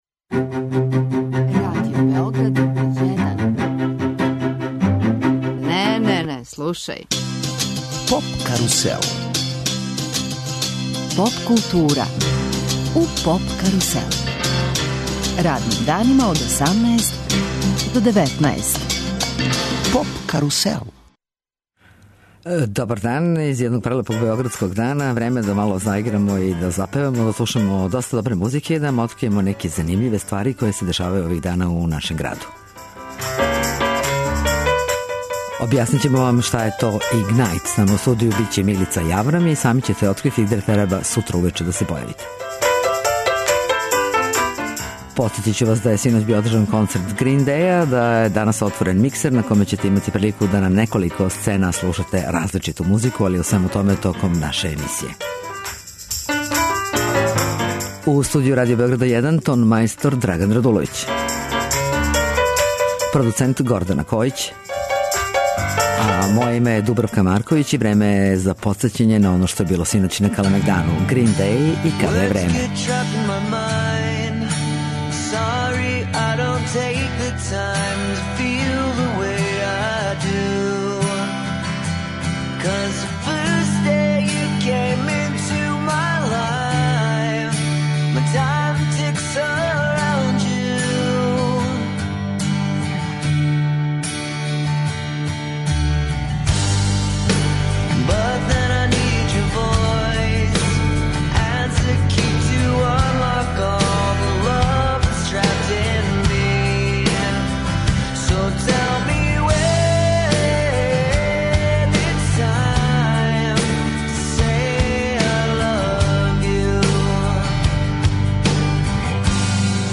Отварање "Миксер" фестивала уз много музике.